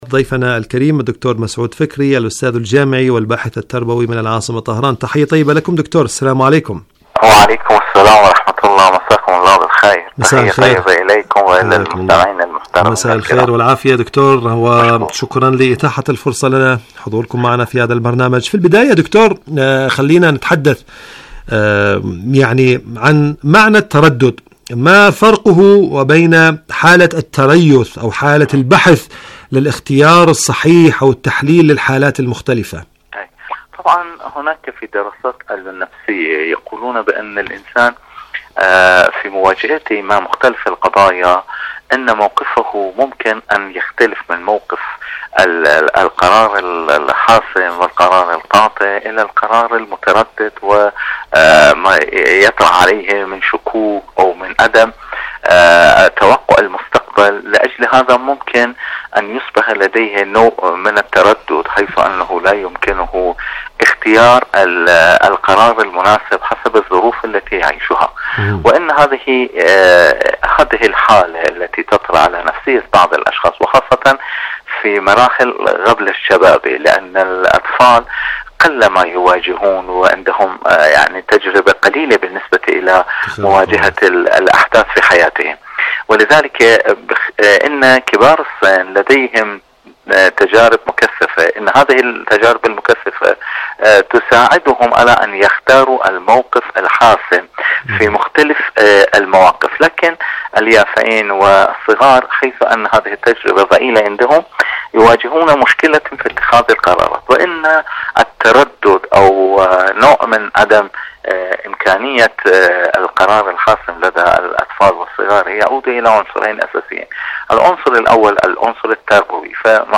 برنامج معكم على الهواء مقابلات إذاعية